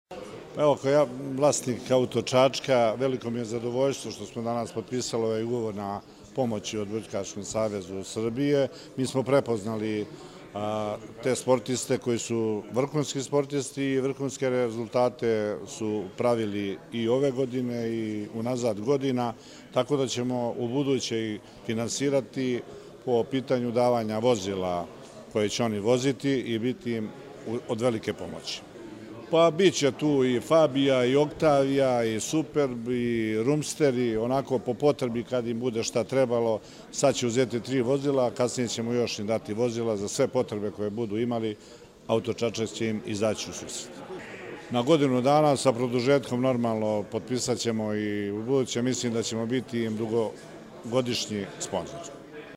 U prostorijama kompanije “Auto Čačak” na Novom Beogradu, danas je svečano potpisan Ugovor o saradnji između kompanije “Auto Čačak” i Odbojkaškog Saveza Srbije.
IZJAVA